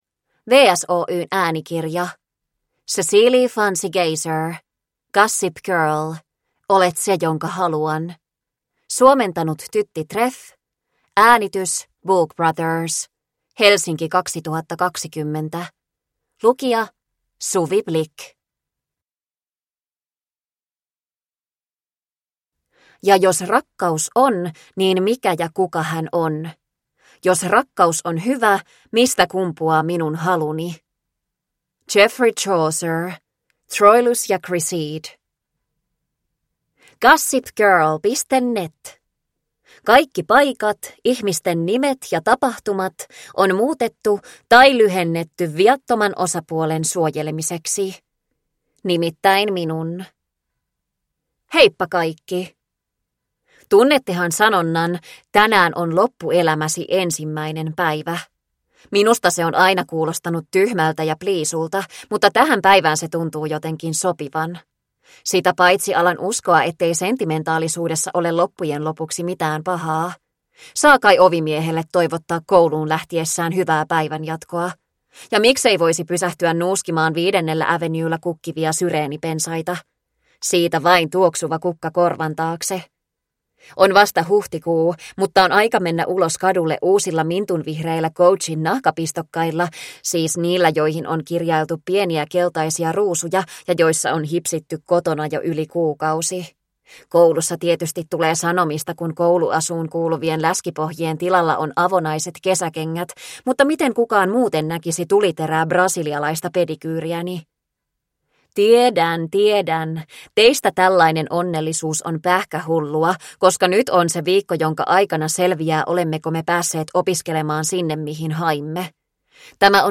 Olet se jonka haluan – Ljudbok – Laddas ner